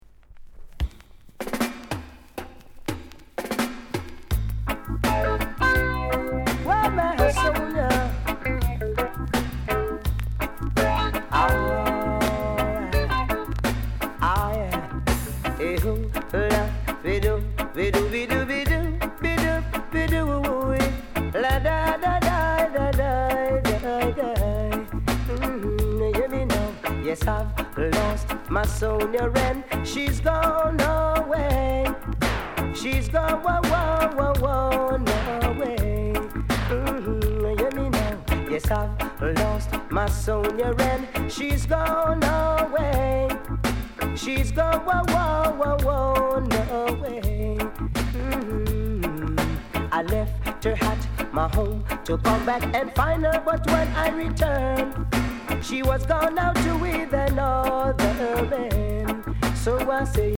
Sound Condition VG